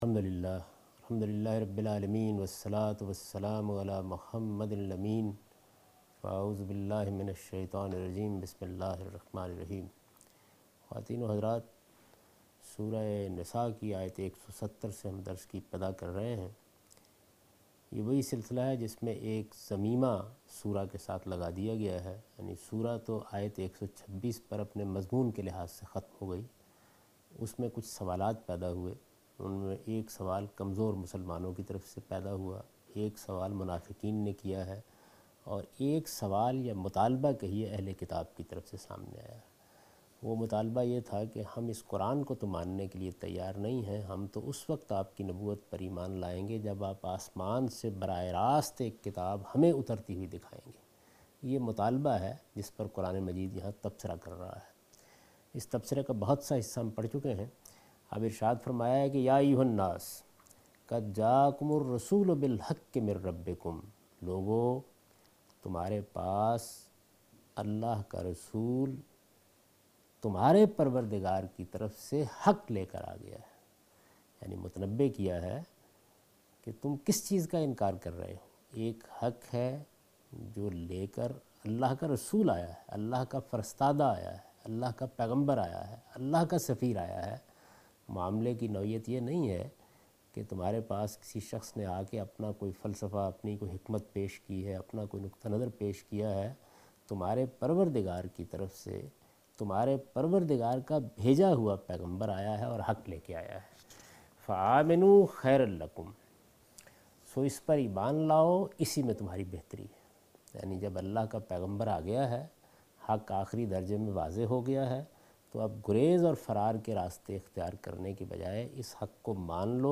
Surah Al-Nisa - A Lecture of Tafseer ul Quran Al-Bayan by Javed Ahmed Ghamidi